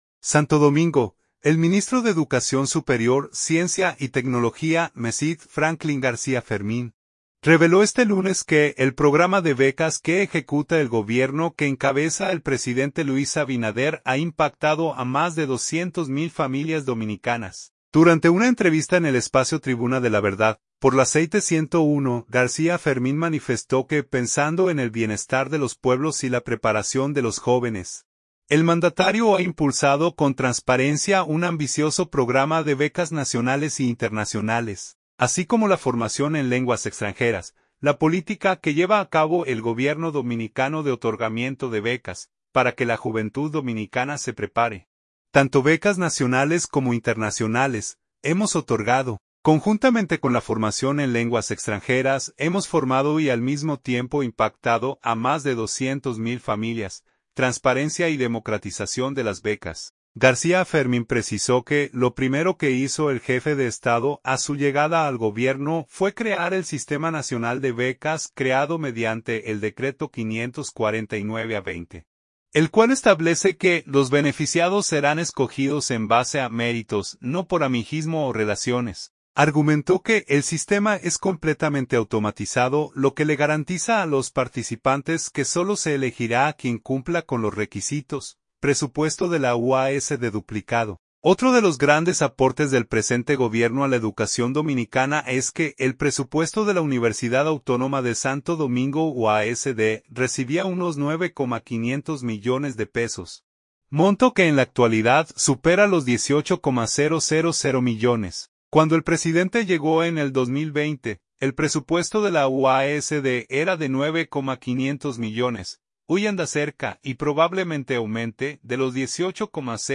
Durante una entrevista en el espacio Tribuna de la Verdad, por la Z101, García Fermín manifestó que pensando en el bienestar de los pueblos y la preparación de los jóvenes, el mandatario ha impulsado con transparencia un ambicioso programa de becas nacionales e internacionales, así como la formación en lenguas extranjeras.